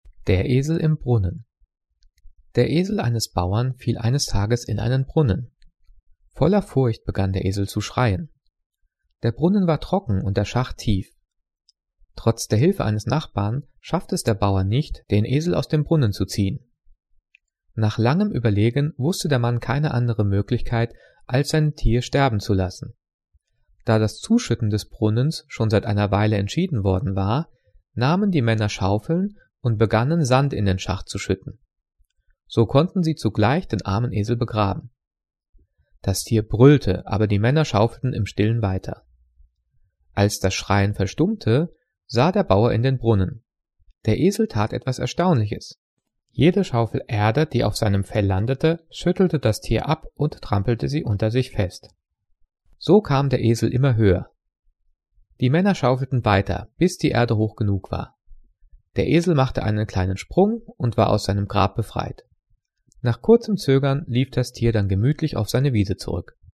Gelesen:
gelesen-der-esel-im-brunnen.mp3